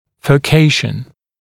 [fɜː’keɪʃ(ə)n][фё:’кейш(э)н]фуркация, разветвление, раздвоение, зона разделения корней многокорневых зубов